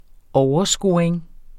Udtale [ ˈɒwʌˌsgoːɐ̯eŋ ]